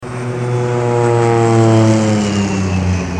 AVION
Ambient sound effects
Descargar EFECTO DE SONIDO DE AMBIENTE AVION - Tono móvil
avion.mp3